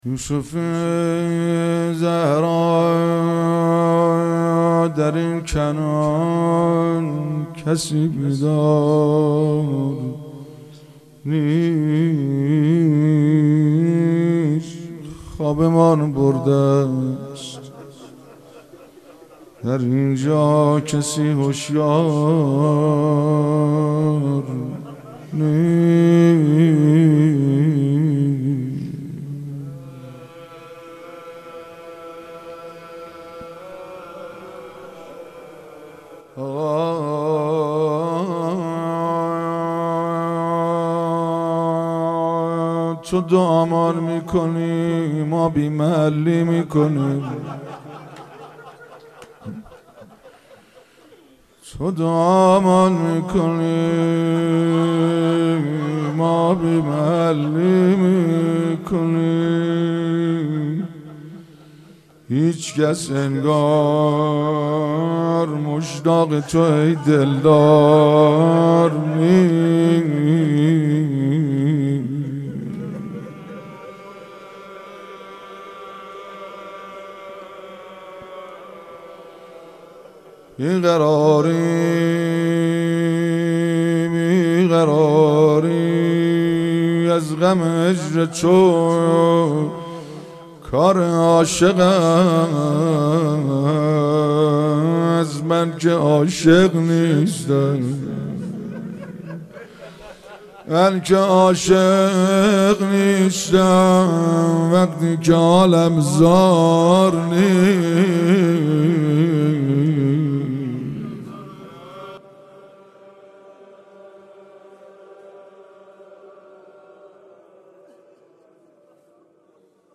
مناجات با امام زمان(عج)